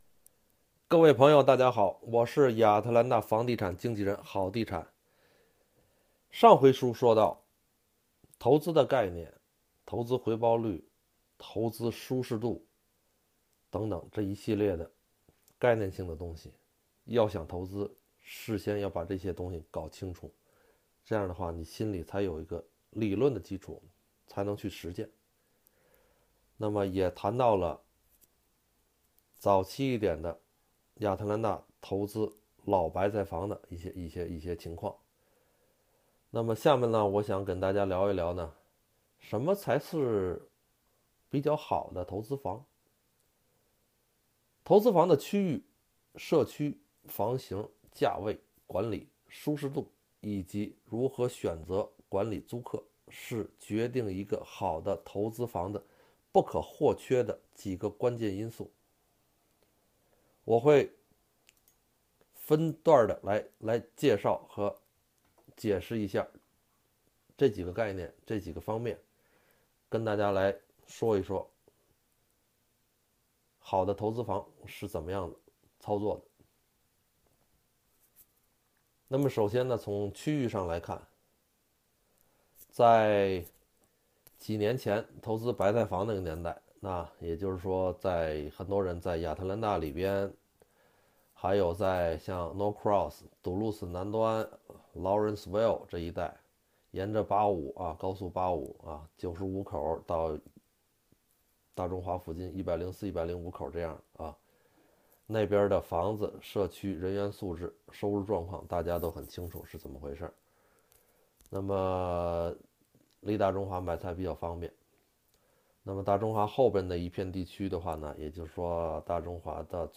【语音讲座】投资房(3)-1